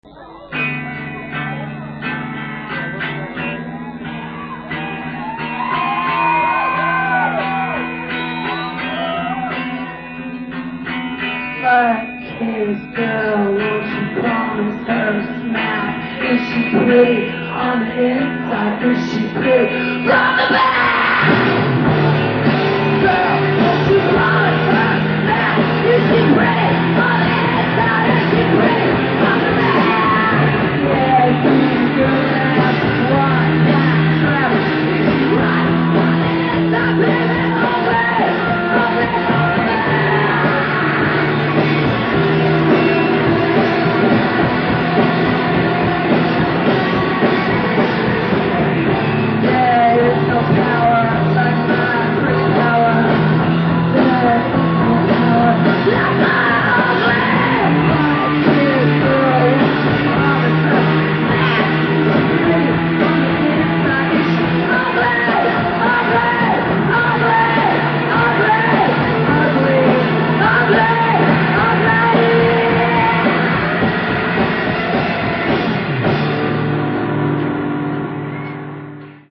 live 1990-1992
The Whiskey A Go-Go, Hollywood, CA